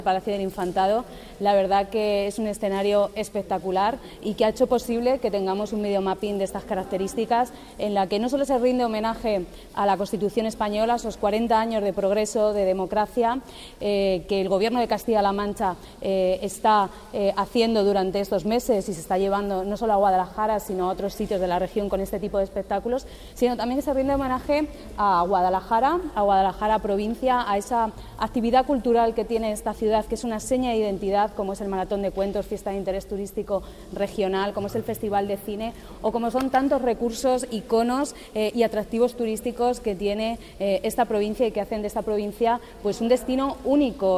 La directora general de Turismo, Comercio y Artesanía, Ana Isabel Fernández Samper, habla del video mapping conmemorativo de la Constitución proyectado en Guadalajara.